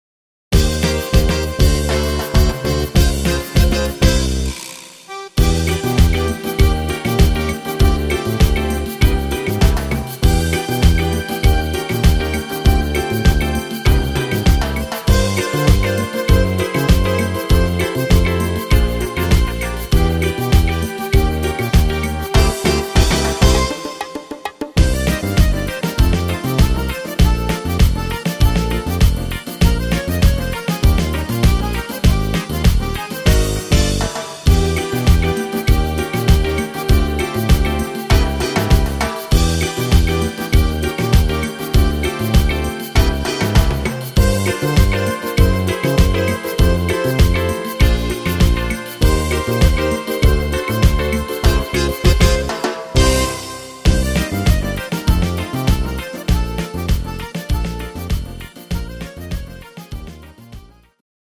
Bar Piano